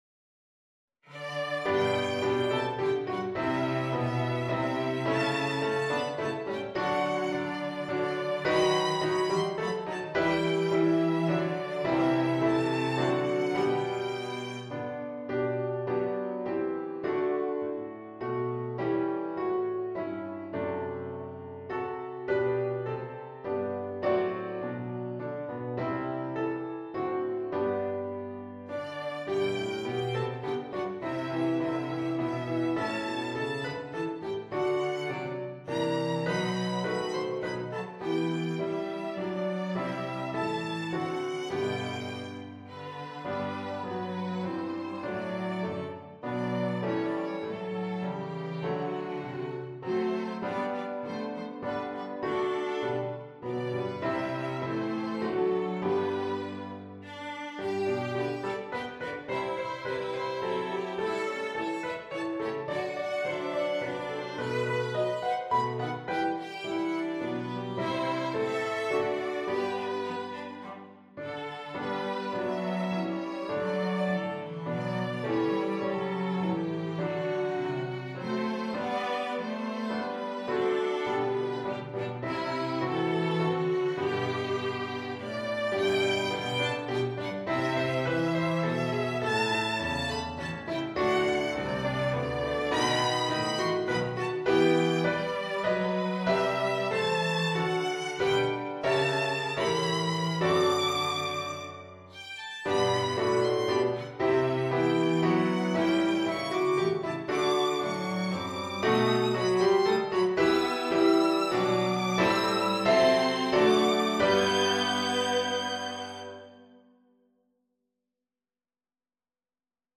Adapted and arranged for Piano Trio
Christmas, Folk and World, Spirituals, Thanksgiving